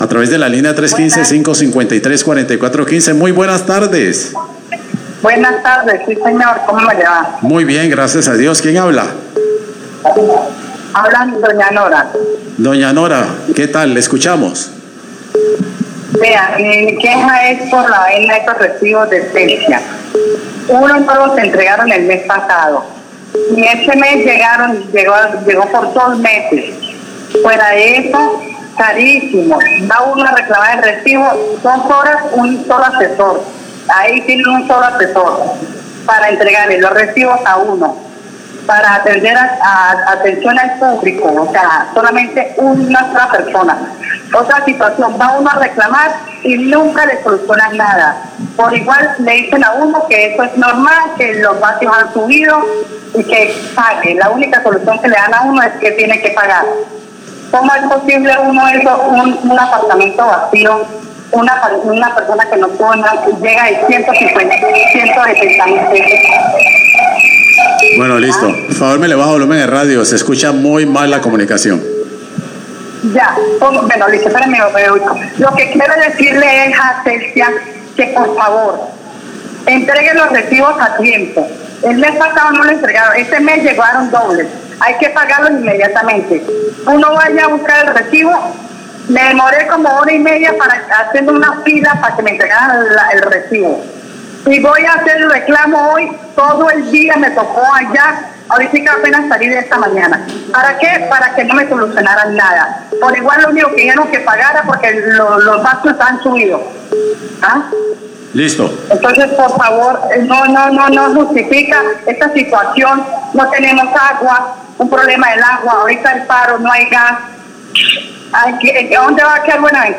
Oyente se queja por no entrega de recibo energía y mala atención en Celsia